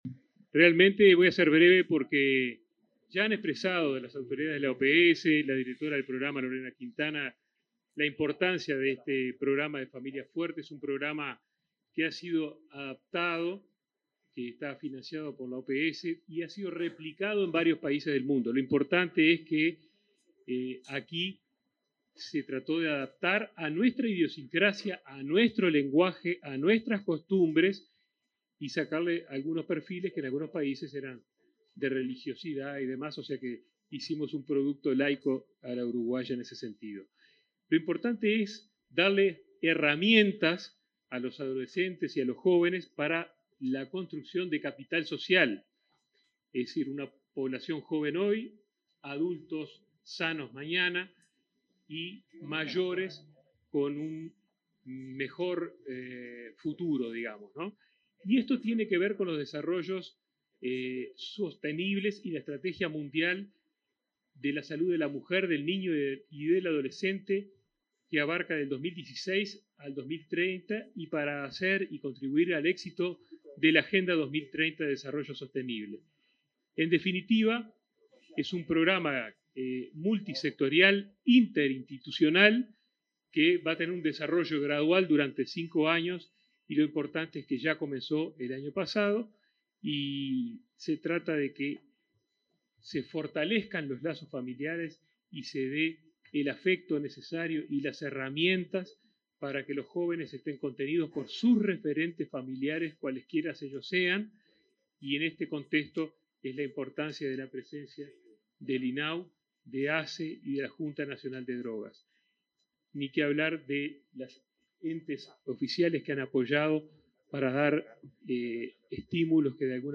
Palabras de autoridades en lanzamiento de programa Familias Fuertes
El ministro de Salud Pública, Daniel Salinas; el presidente de ASSE, Leonardo Cipriani; y el titular del INAU, Pablo Abdala, participaron este